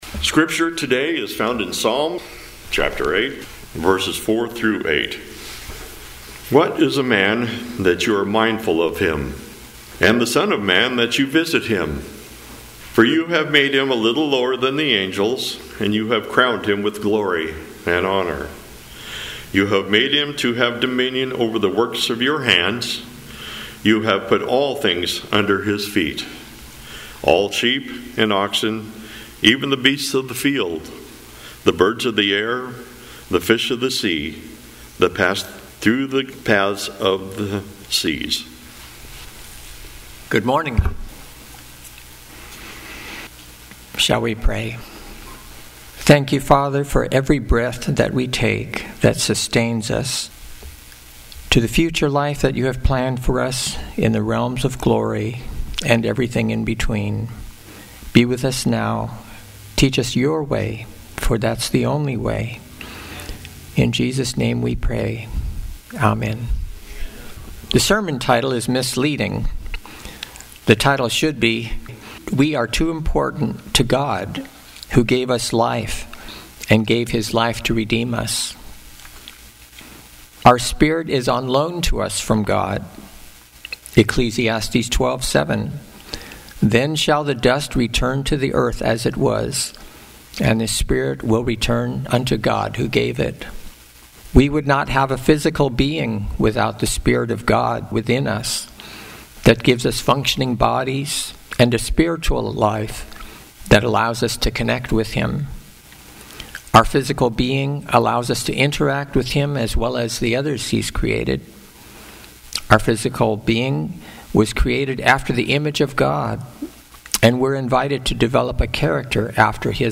Seventh-day Adventist Church, Sutherlin Oregon
Sermons and Talks 2024